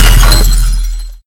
KillsoundUltrakill.ogg